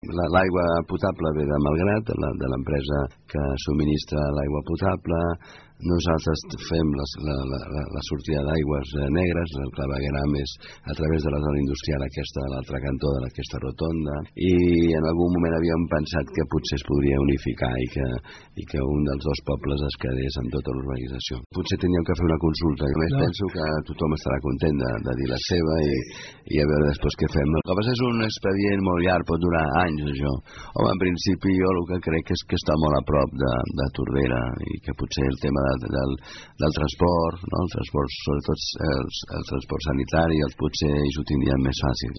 En una entrevista al programa “La tarda és temps era temps” de Ràdio Tordera, l’alcalde palafollenc, Valentí Agustí, ha proposat fer una consulta popular als veïns de la urbanització per tal que la zona passi a ser gestionada només per un dels dos municipis.